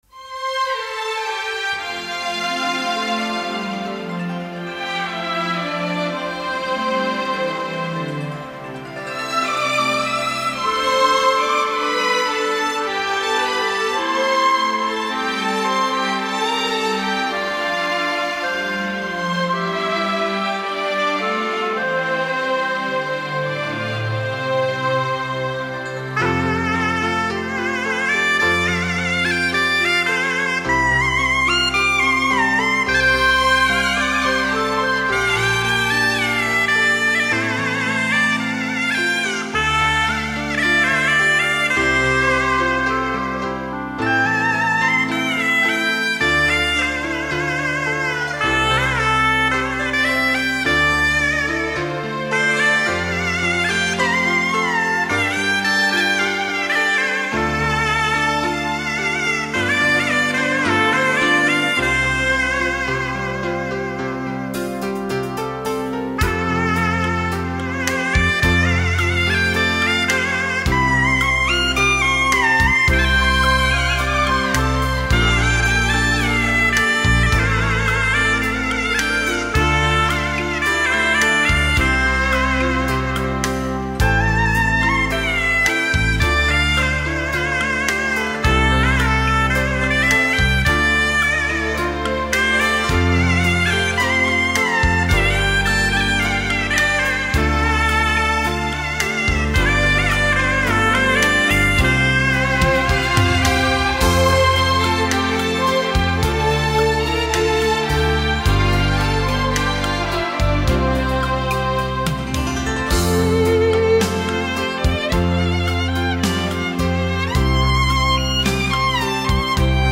音乐类型：唢呐